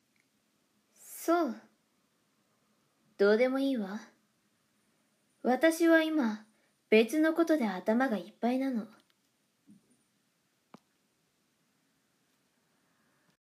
サンプルボイス クール 【少女】